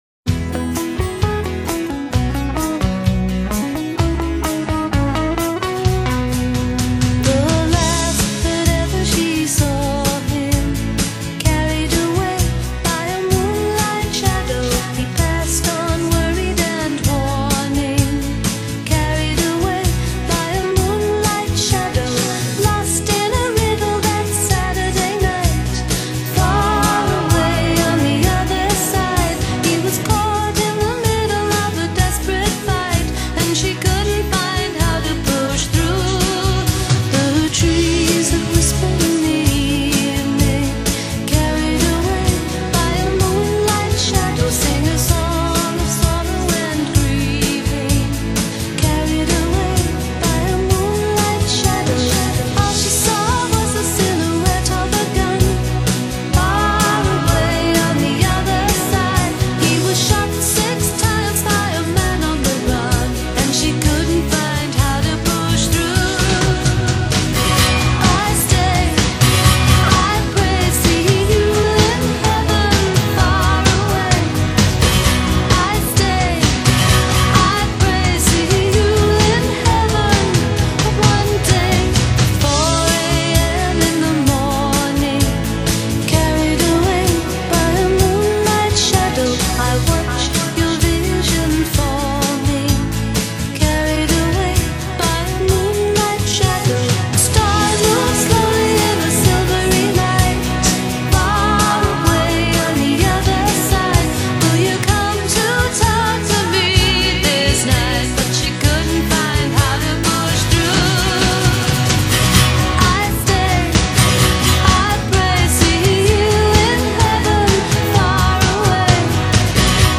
almost haunting at times and then finally fading away.